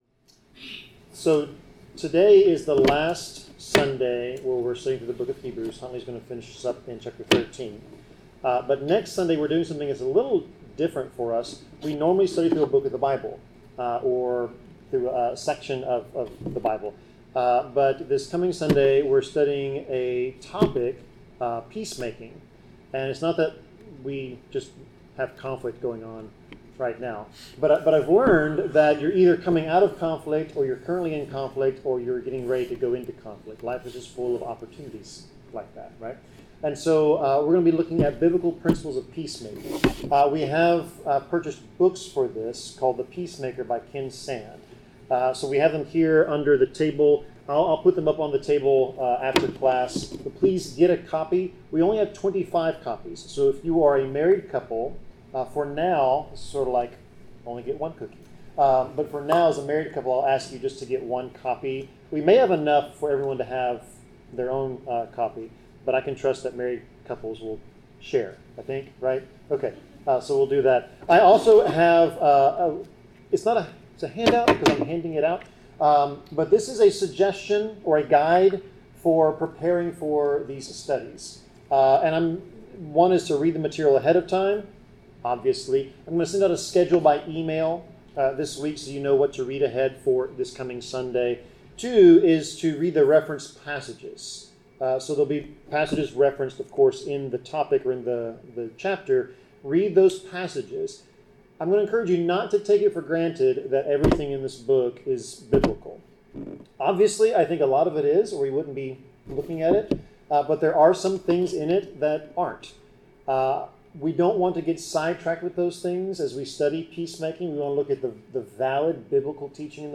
Bible class: Hebrews 13
Service Type: Bible Class